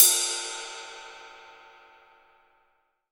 Index of /90_sSampleCDs/AKAI S6000 CD-ROM - Volume 3/Ride_Cymbal1/20INCH_ZIL_RIDE